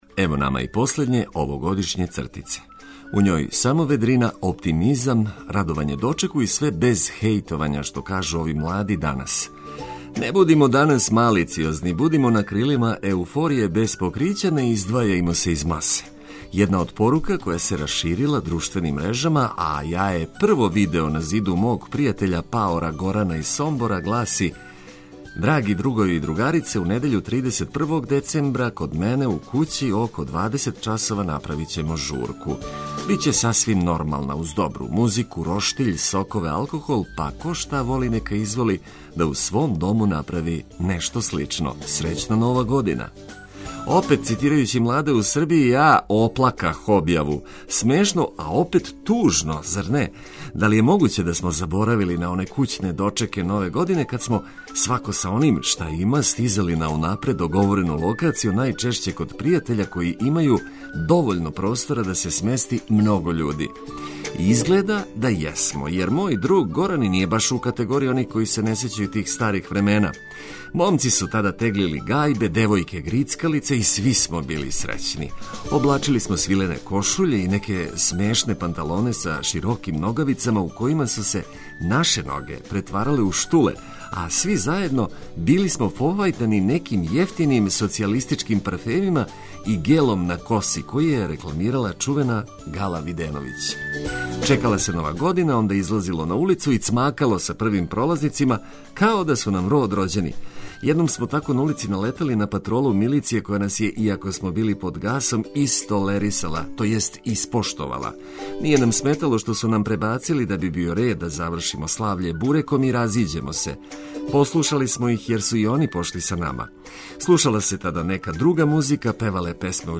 Све важне информације су на једном месту уз пригодну музику и добро расположену екипу па нема разлога да одустанете од буђења у нашем друштву.